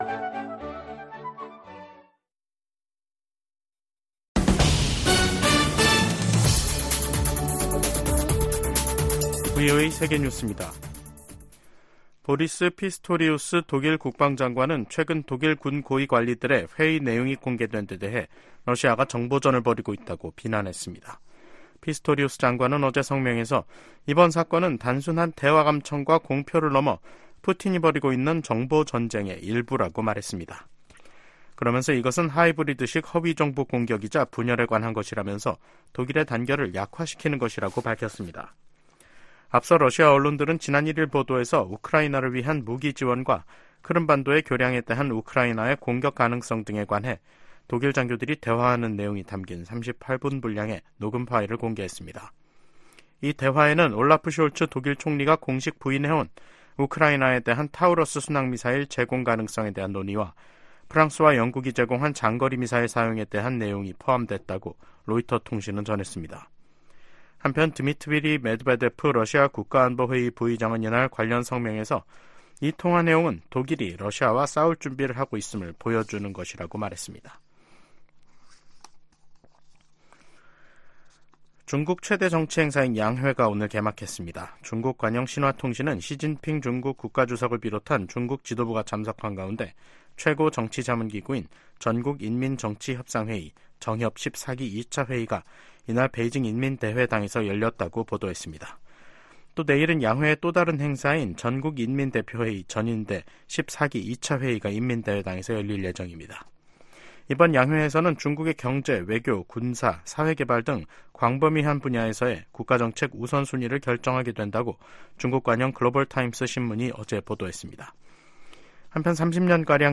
VOA 한국어 간판 뉴스 프로그램 '뉴스 투데이', 2024년 3월 4일 2부 방송입니다. 백악관 고위 관리가 한반도의 완전한 비핵화 정책 목표에 변함이 없다면서도 '중간 조치'가 있을 수 있다고 말했습니다. 유엔 안전보장이사회 순회 의장국 일본이 북한 핵 문제에 대한 국제적 대응에 나설 것이라고 밝혔습니다. 미 상원 중진의원이 중국 수산물 공장의 북한 강제 노동 이용은 현대판 노예 범죄라며, 관련 상품 수입 금지를 조 바이든 행정부에 촉구했습니다.